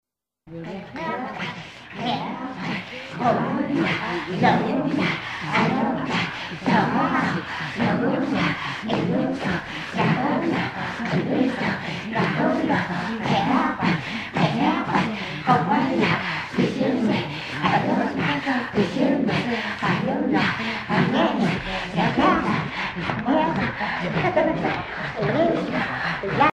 Qiarpaa (jeu vocal par une foule)
chants des Inuïts